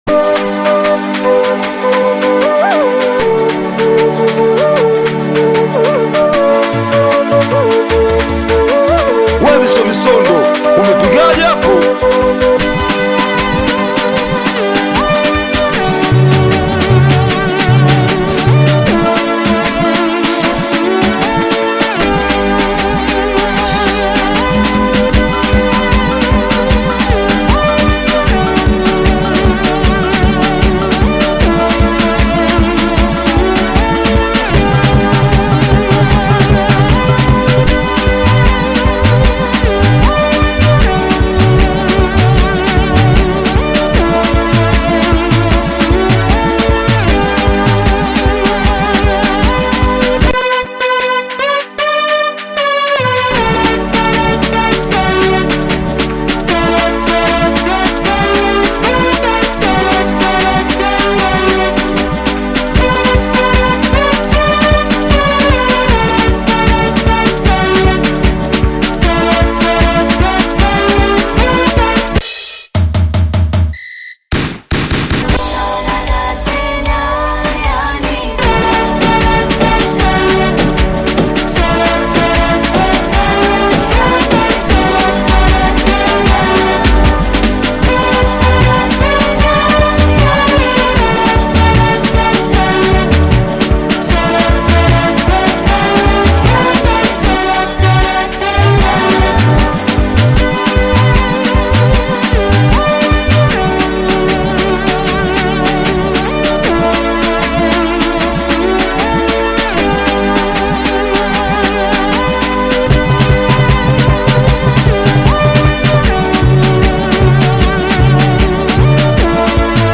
Singeli music track
Tanzanian Bongo Flava Singeli